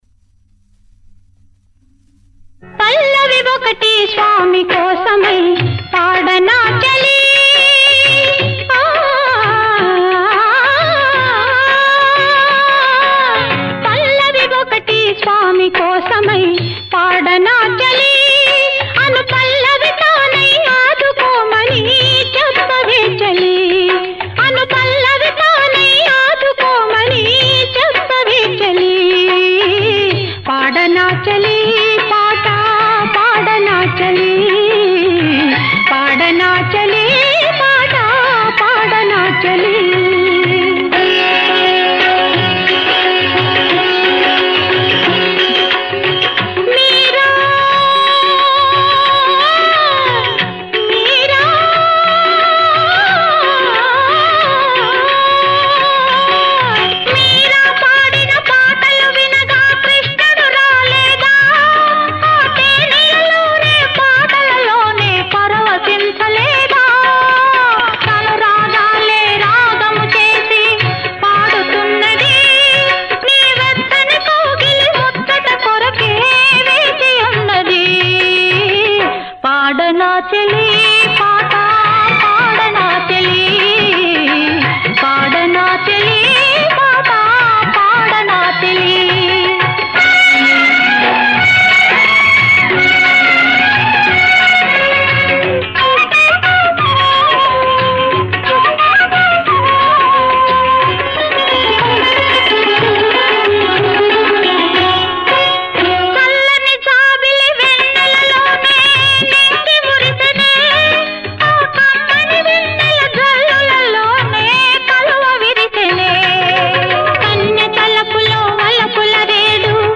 యుగళ గీతం